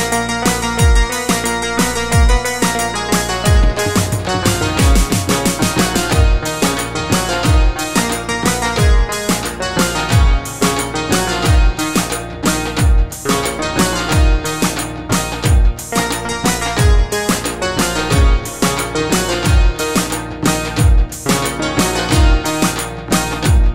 Category: Tabla Ringtones
Featured in Tabla Ringtones